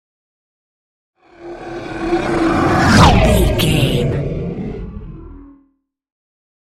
Horror creature vehicle pass by
Sound Effects
Atonal
ominous
eerie
pass by